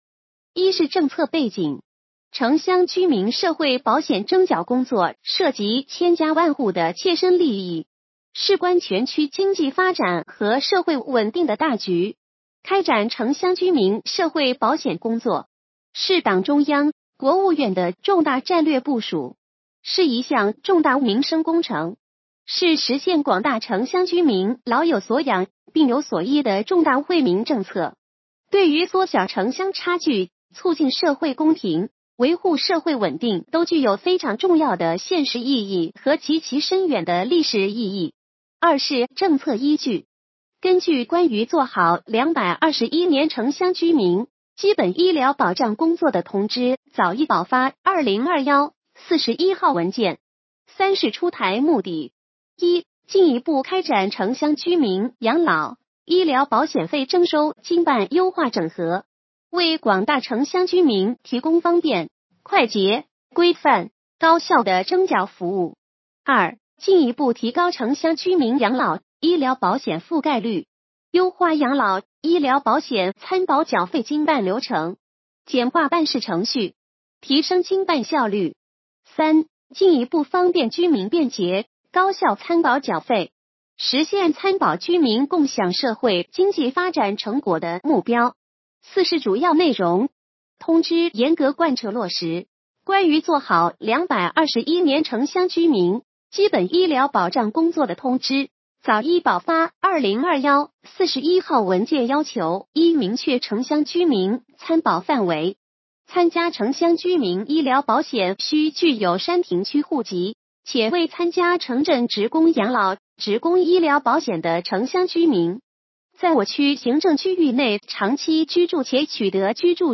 语音解读：山亭区人民政府办公室关于《山亭区2022年度城乡居民基本养老、基本医疗保险集中征缴工作实施方案》